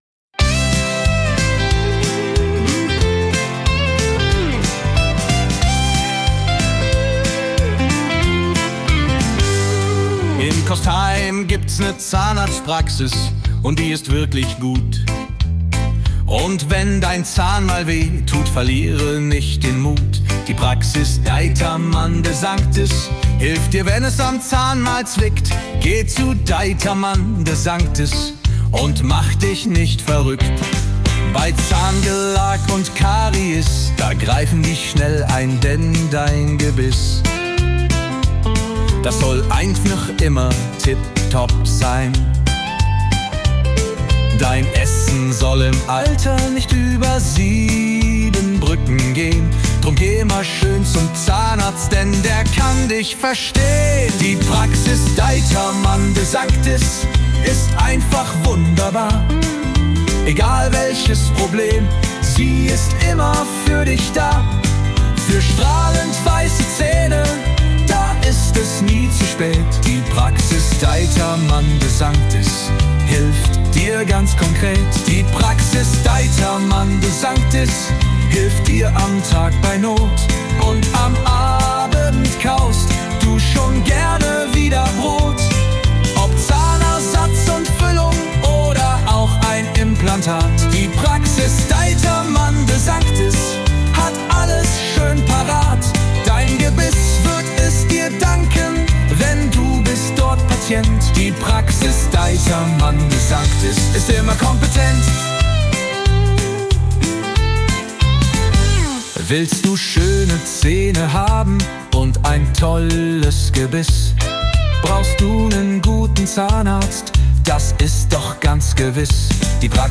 Mit Hilfe von KI erstellt.